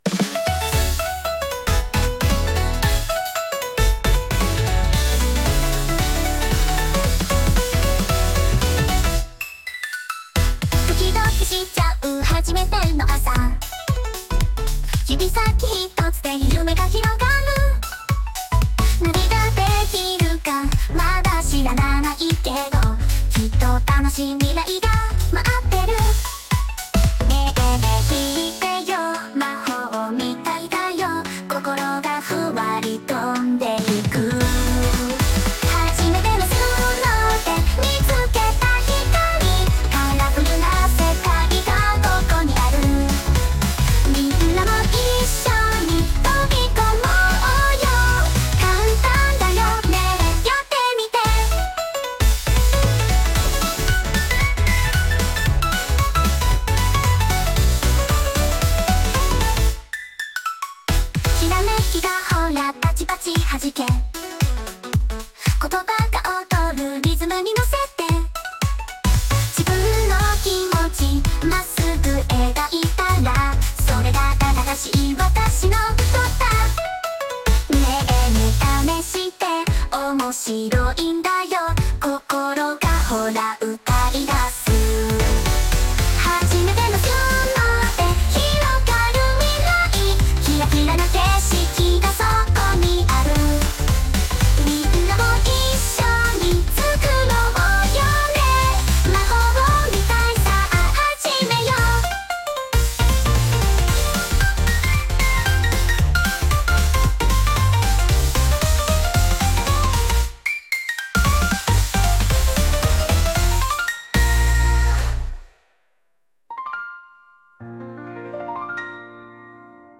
これはプロンプト（曲の説明）も不要で、「曲のテーマ」や「雰囲気」を日本語で入力するだけで、AIが歌までつけて1曲作ってくれるんです！
「アニメのオープニングみたいな、元気で楽しい可愛い感じの曲。」と入力してみました。